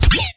shoot1.mp3